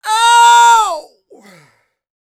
C-YELL 1201.wav